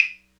Perc [ Dot ].wav